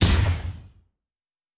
Sci Fi Explosion 18.wav